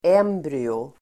Ladda ner uttalet
Uttal: ['em:bryo]